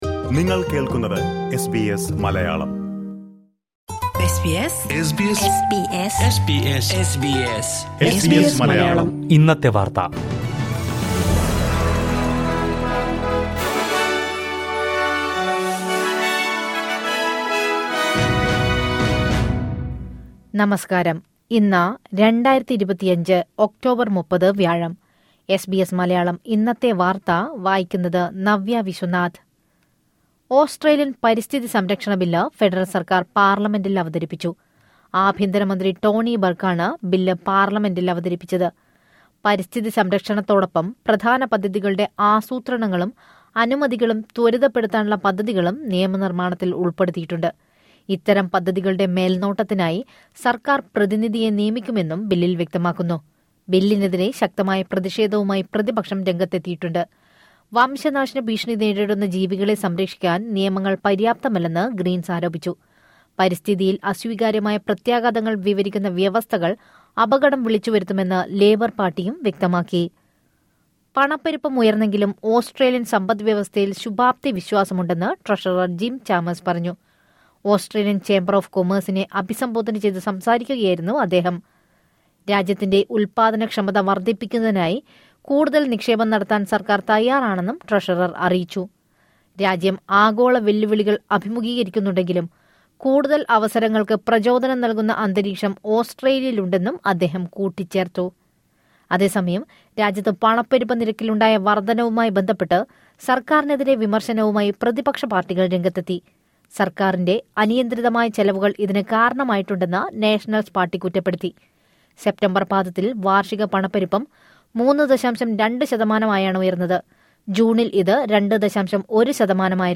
2025 ഒക്ടോബർ 30ലെ ഓസ്ട്രേലിയയിലെ ഏറ്റവും പ്രധാന വാർത്തകൾ കേൾക്കാം...